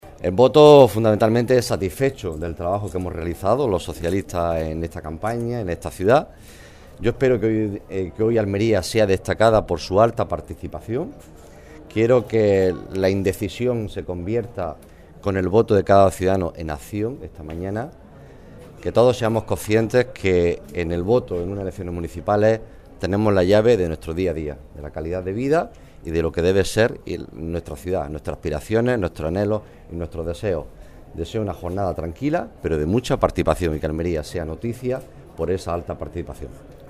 En declaraciones a los medios de comunicación